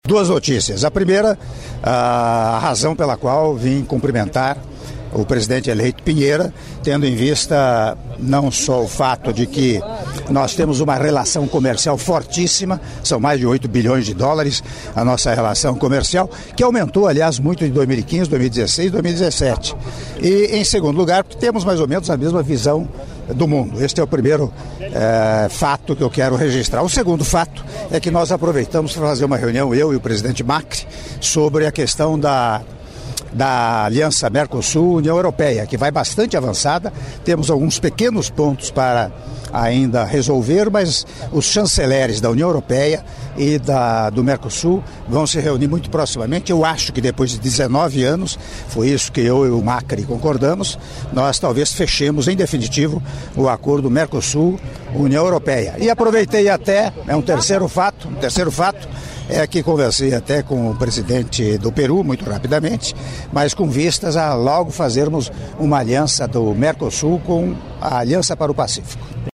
Áudio da entrevista concedida pelo Presidente da República, Michel Temer, após Cerimônia Oficial de Transmissão do Mandato Presidencial para o Presidente-eleito do Chile, Senhor Sebastián Piñera - (01min09s) - Viña del Mar/Chile